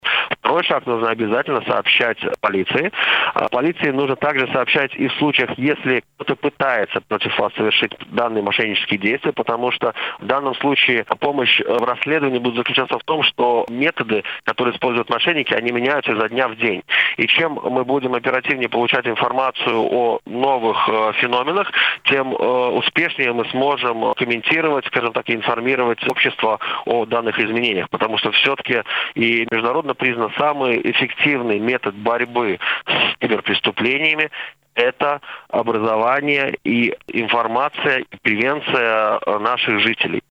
На радио Baltkom сегодня журналисты обсуждали несколько тем - как общество спасается от депрессии, как полиция борется с кибермошенниками и что делает парламентская оппозиция в период принятия решение по COVID.